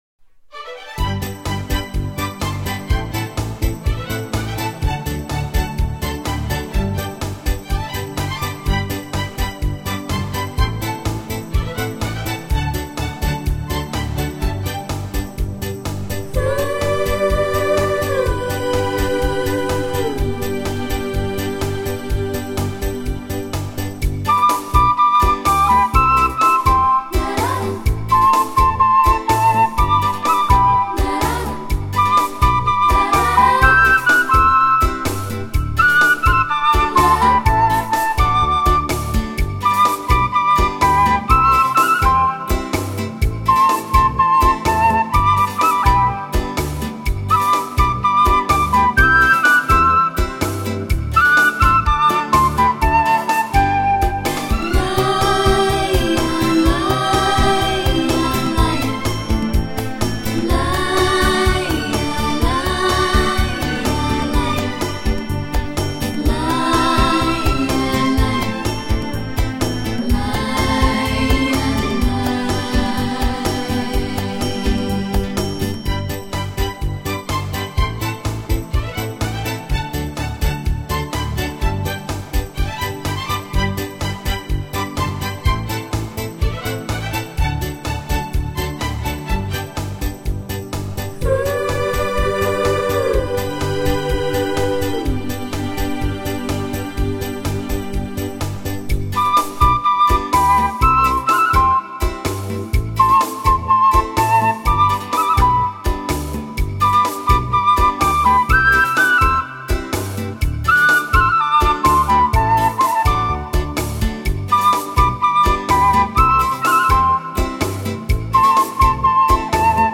竹笛、箫、弦乐，人声